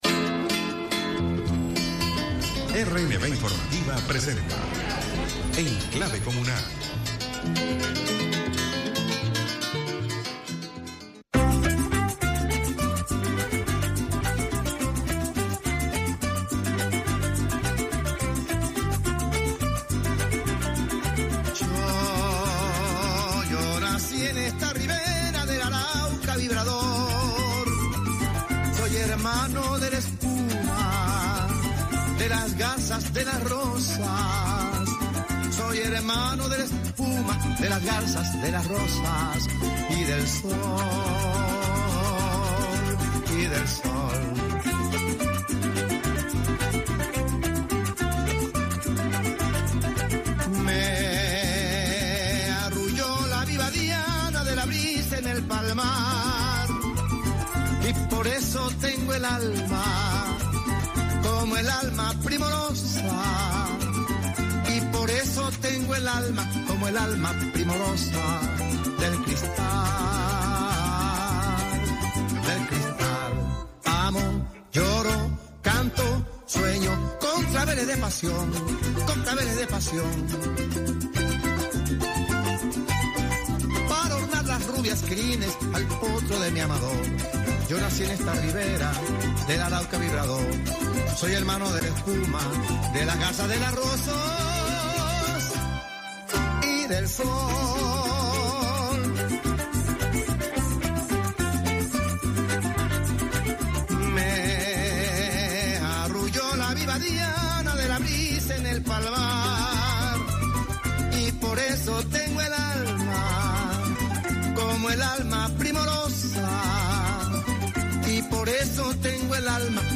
programa radiofónico En clave comunal